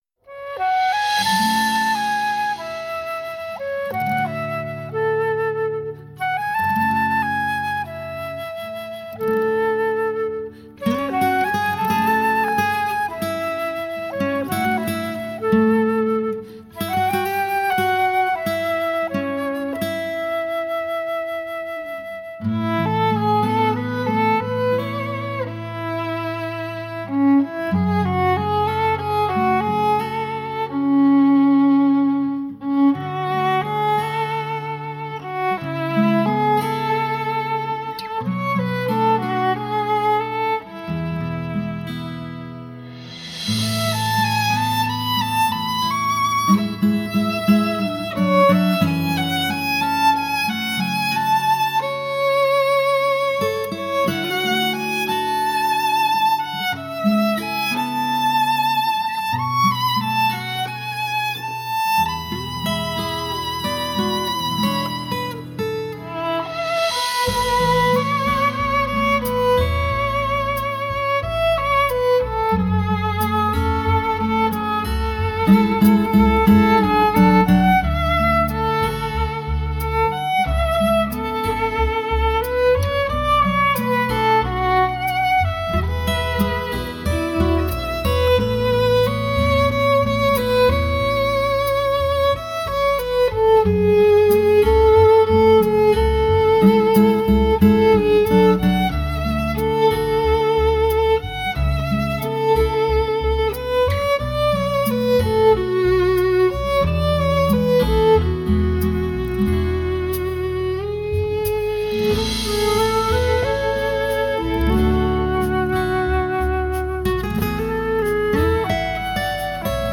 小提琴演奏
精湛的录音，混音功不可没。